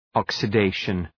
Προφορά
{,ɒksə’deıʃən} (Ουσιαστικό) ● οξείδωση